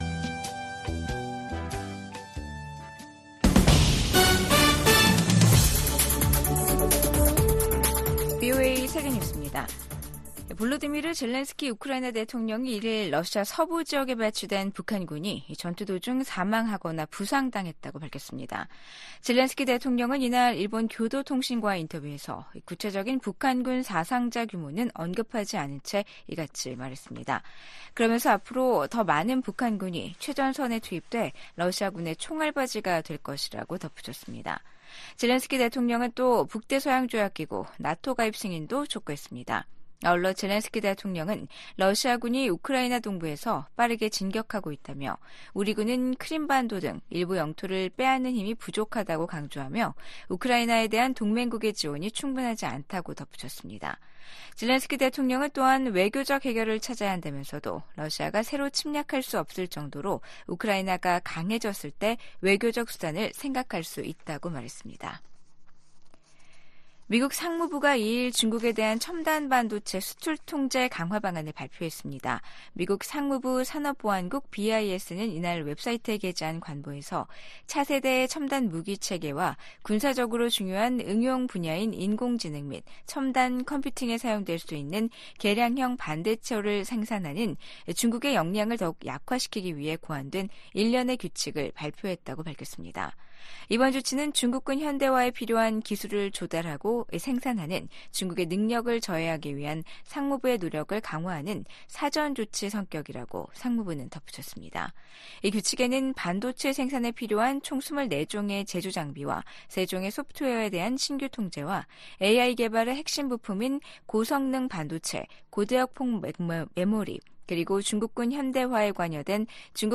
VOA 한국어 아침 뉴스 프로그램 '워싱턴 뉴스 광장'입니다. 김정은 북한 국무위원장이 북한을 방문한 안드레이 벨로우소프 러시아 국방장관을 만나 우크라이나 전쟁과 관련해 러시아에 대한 지지 입장을 거듭 분명히 했습니다. 미국 국무부는 러시아 국방장관의 북한 공식 방문과 관련해 북러 협력 심화에 대한 우려 입장을 재확인했습니다.